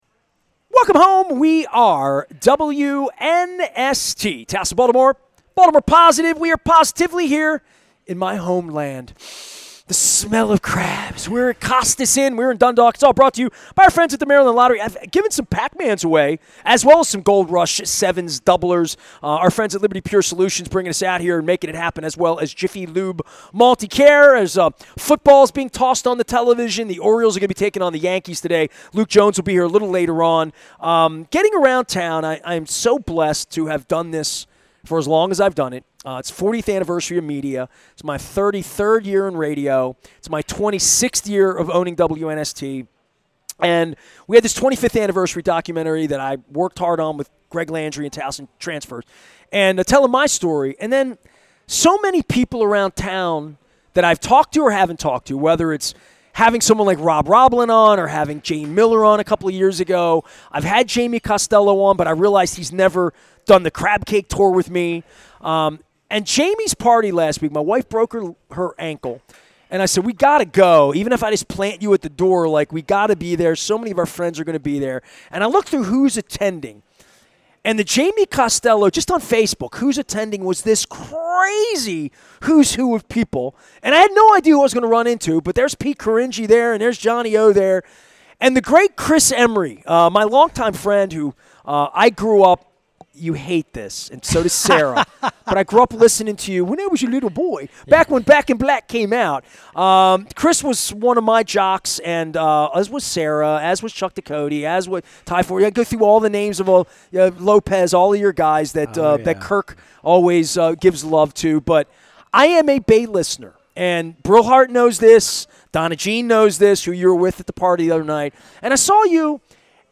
at Costas Inn on the Maryland Crab Cake Tour.